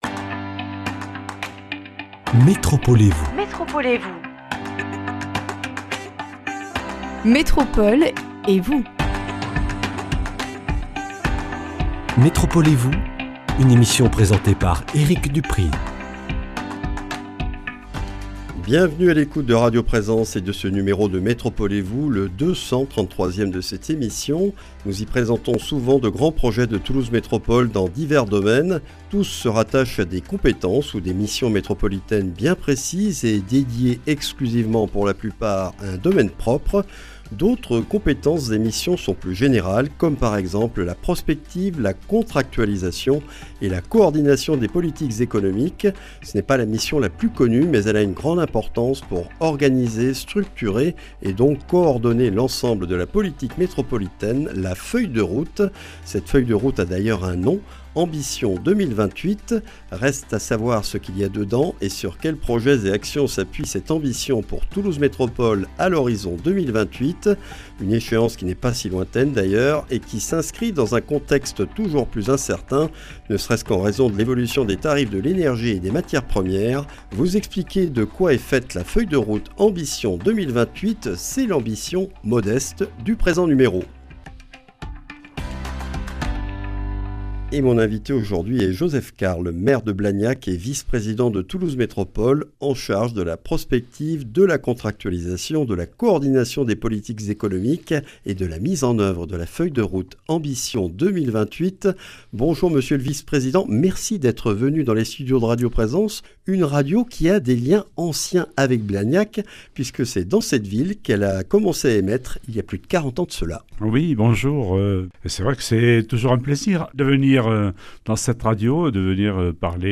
Ambition 2028 est le nom donné à la feuille de route économique de Toulouse Métropole. Un document structuré autour de 7 projets et de 23 actions que nous présente Joseph Carles, maire de Blagnac, vice-président de Toulouse Métropole en charge de la Prospective, de la Contractualisation, de la Coordination des politiques économiques et de la mise en oeuvre de la feuille de route Ambition 2028.